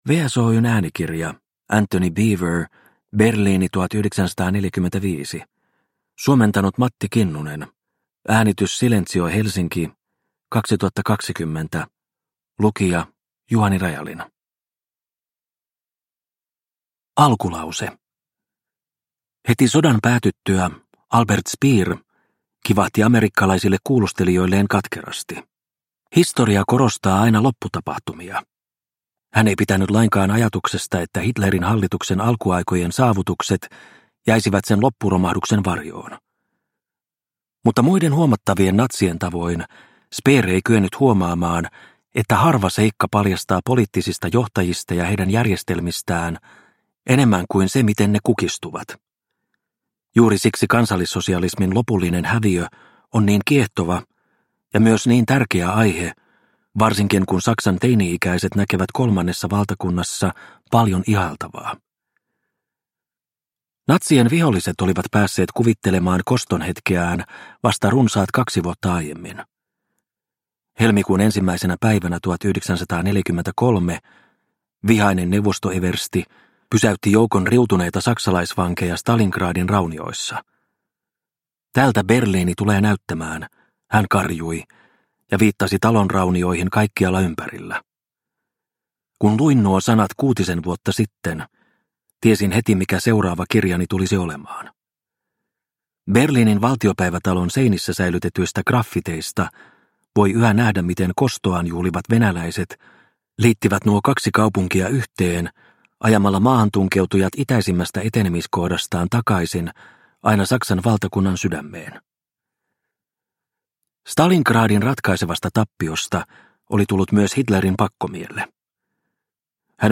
Berliini 1945 – Ljudbok – Laddas ner